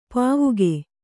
♪ pāvuge